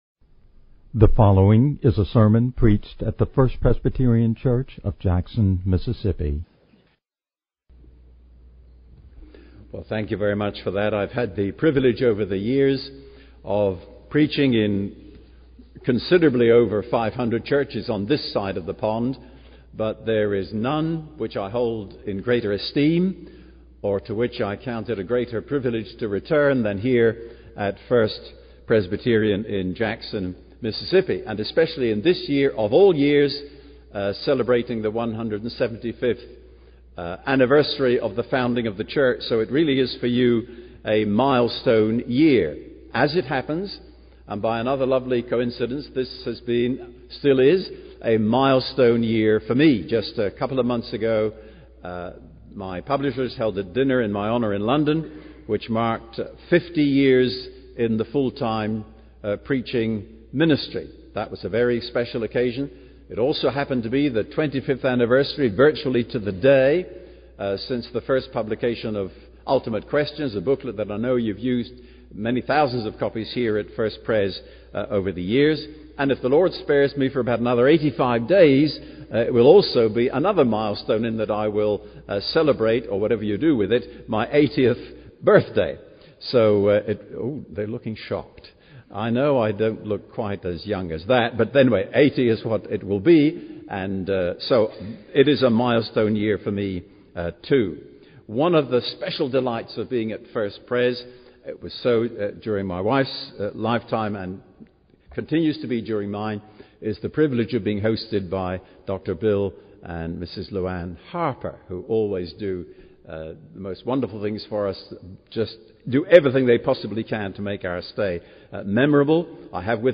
Wednesday Evening Prayer Meeting